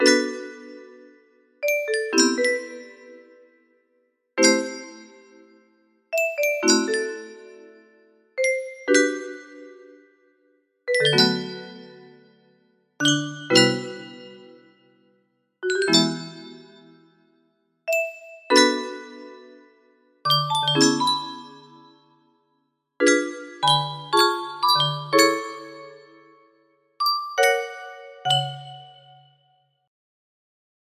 Main music box melody
Full range 60